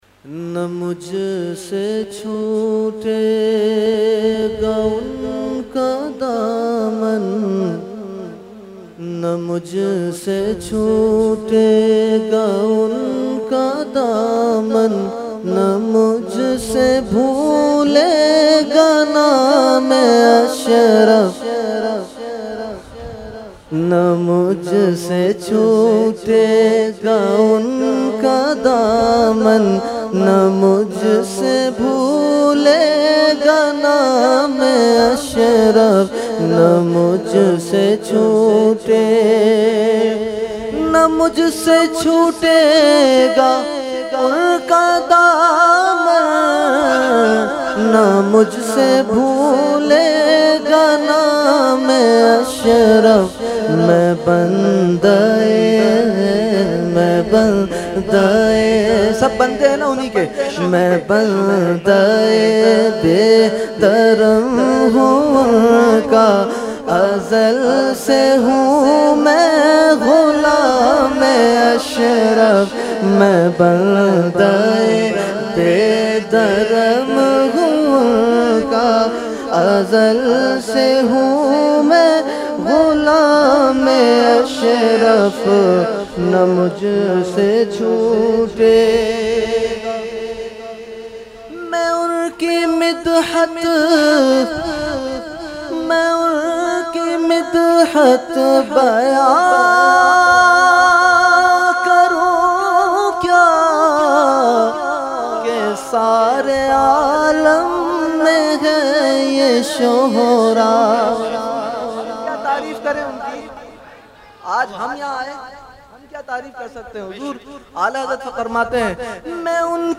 Category : Manqabat | Language : UrduEvent : Urs Makhdoome Samnani 2020